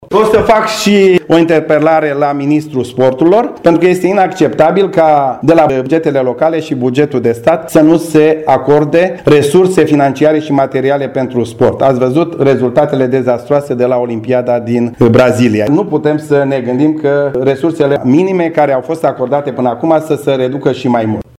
Ministrul dorește să restrângă drastic finanțarea activității sportive, cu bani de la bugetul primăriilor, lucru considerat inadmisibil de Gheorghe Ialomițianu, care amintește și comportarea mediocră a sportivilor noștri la Rio: